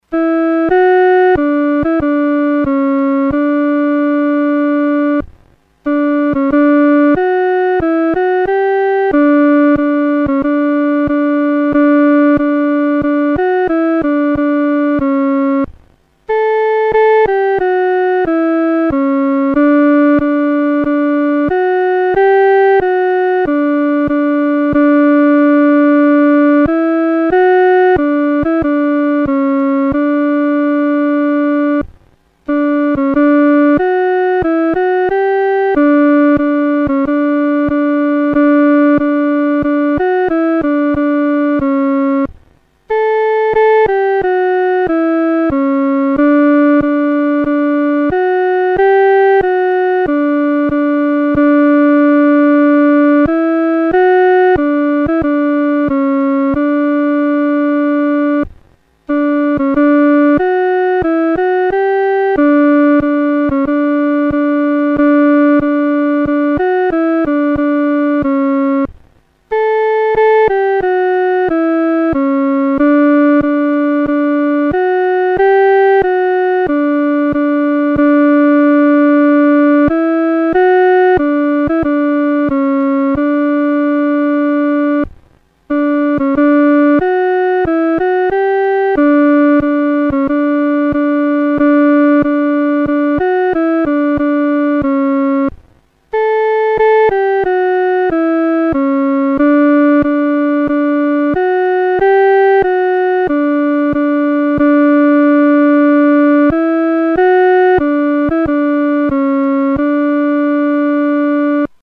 独奏（第二声）
牧人闻信-独奏（第二声）.mp3